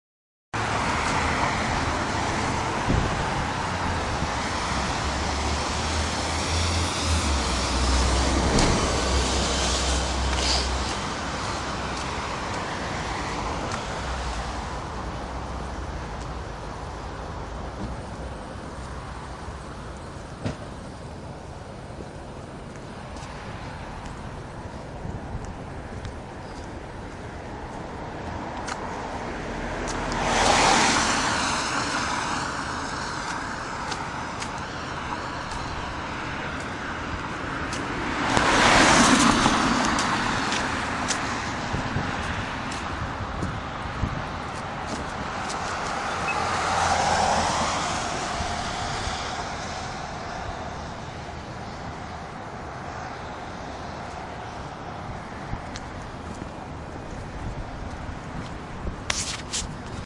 描述：街与汽车在运动中。
Tag: 道路 现场录音 交通 汽车